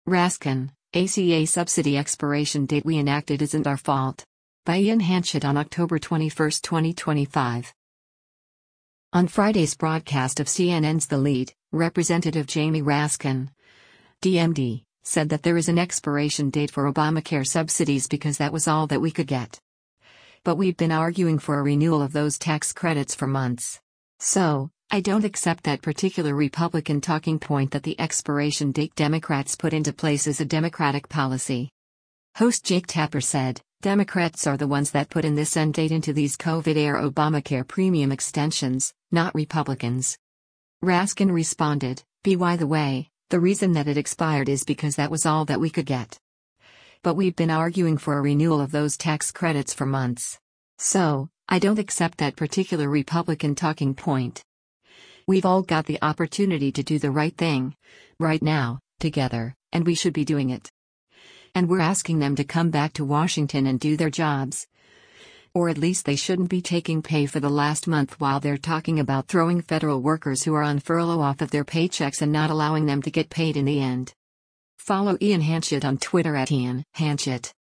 On Friday’s broadcast of CNN’s “The Lead,” Rep. Jamie Raskin (D-MD) said that there is an expiration date for Obamacare subsidies “because that was all that we could get. But we’ve been arguing for a renewal of those tax credits for months. So, I don’t accept that particular Republican talking point” that the expiration date Democrats put into place is a Democratic policy.
Host Jake Tapper said, “Democrats are the ones that put in this end date into these COVID-era Obamacare premium extensions, not Republicans.”